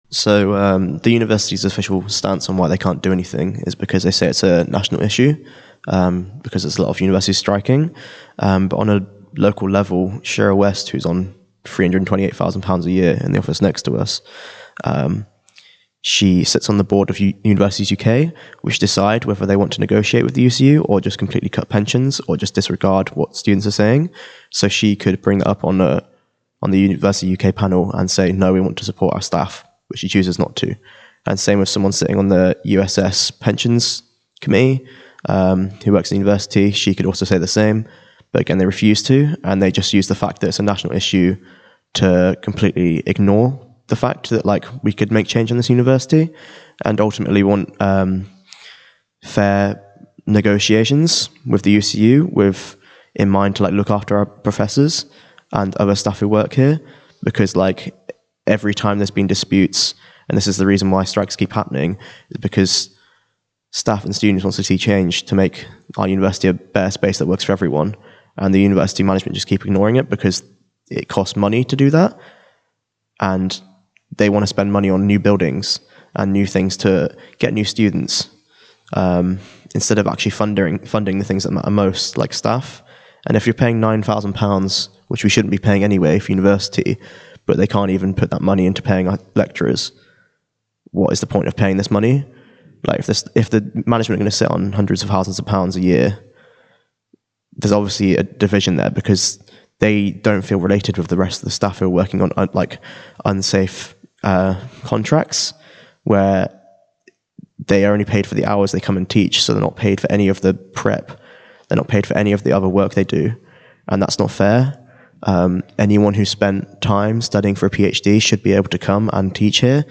Q2: Trent Building Occupiers Interview